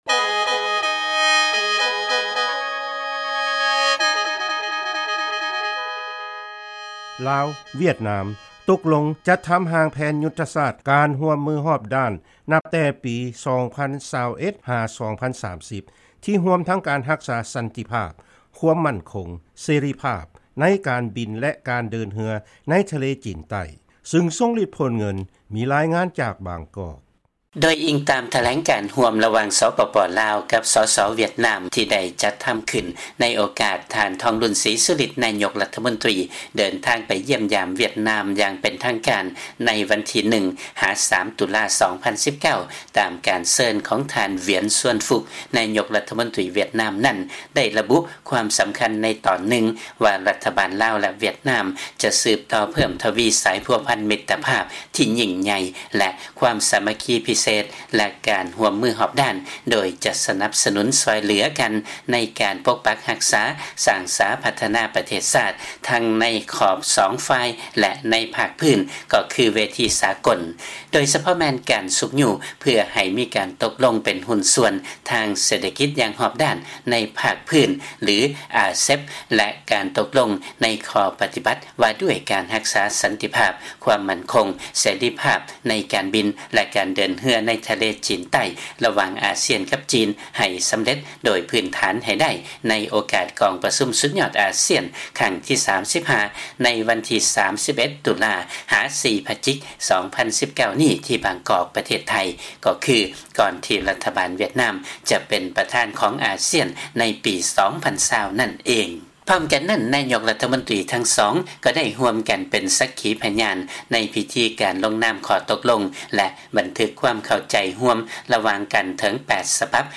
ຟັງລາຍງານ ລາວ-ຫວຽດນາມ ຕົກລົງຈັດທຳ ຮ່າງແຜນຍຸດທະສາດ ການຮ່ວມມື ຮອບດ້ານ ນັບຈາກປີ 2021-2030